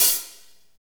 HAT P B LH0J.wav